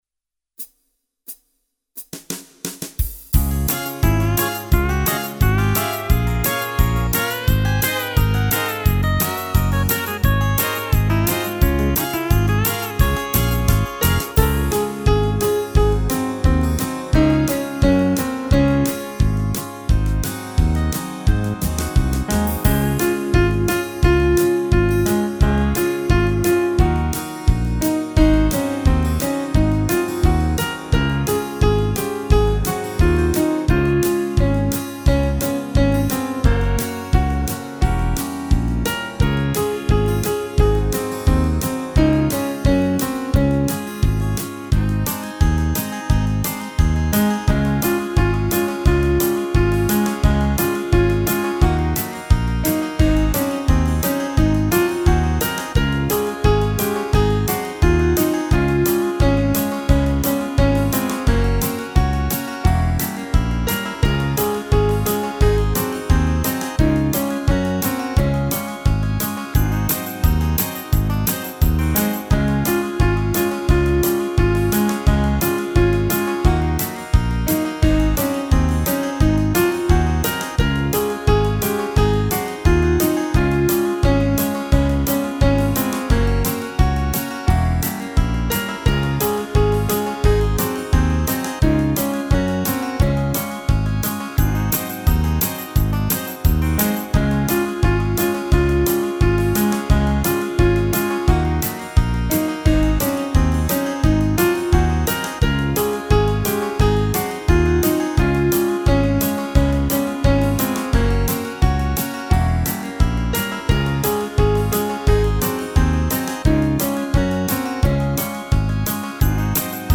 Der spilles 5 vers
Der spilles lang forspil –
Start med at synge efter 22 sekunder